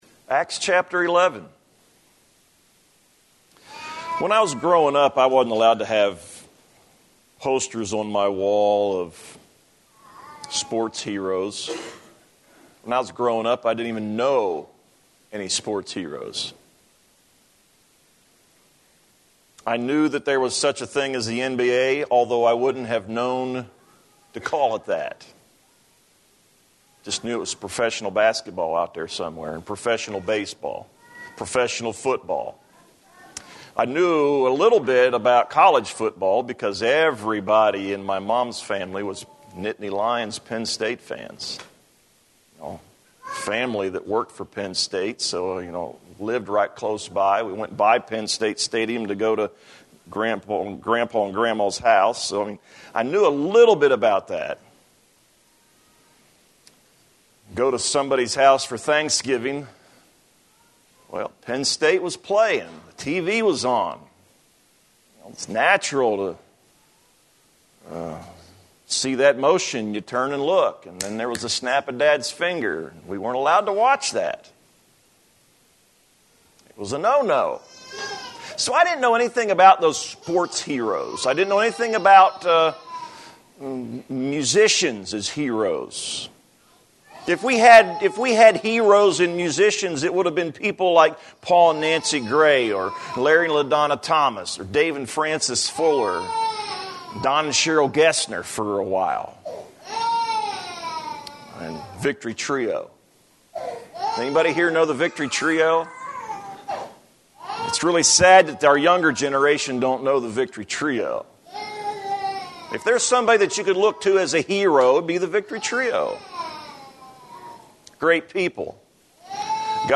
A message